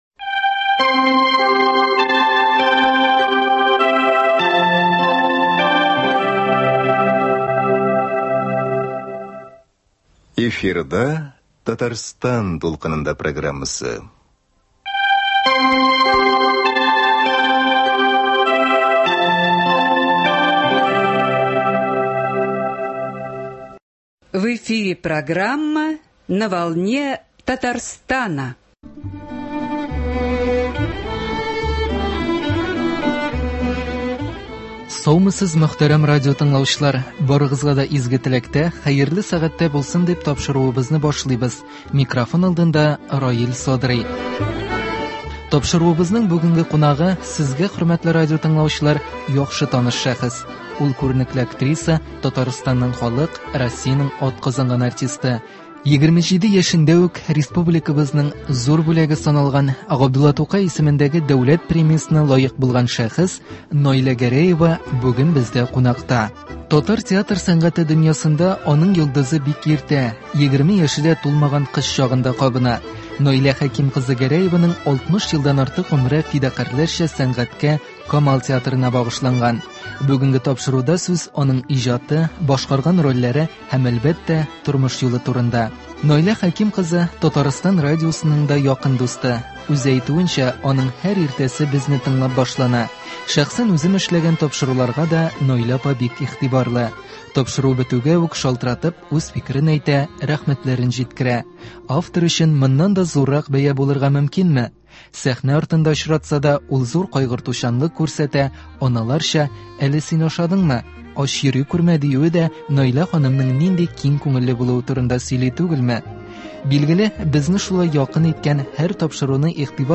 Тапшыруыбызның бүгенге кунагы – сезгә, хөрмәтле радиотыңлаучылар, яхшы таныш шәхес.
Нибары 27 яшендә республикабызның Г.Тукай исемендәге Дәүләт премиясенә лаек булган шәхес — Наилә Гәрәева бүген бездә кунакта.